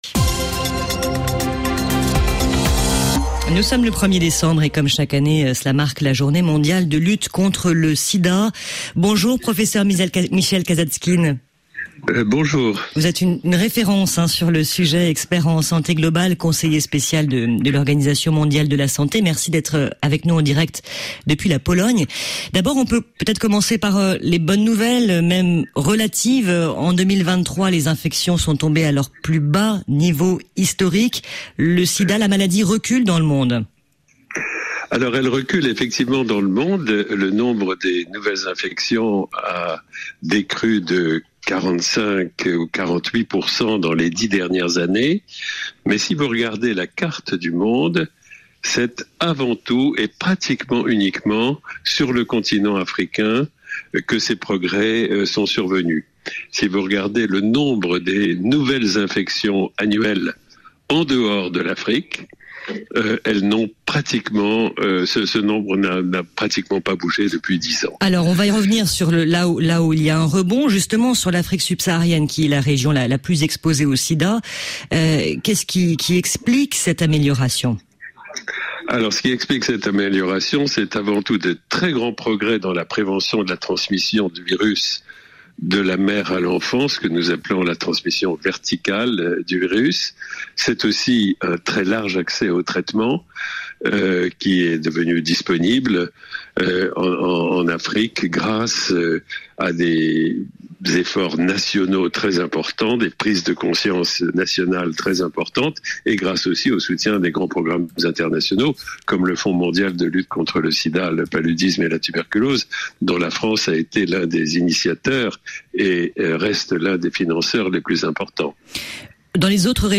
Où en est-on aujourd’hui sur le front de la prévention et des traitements anti-Sida ? Pour en parler, le Professeur Michel Kazatchkine, expert en santé globale et conseiller spécial de l’Organisation mondiale de la santé (OMS) est, en direct de la Pologne, l’invité international de la mi-journée.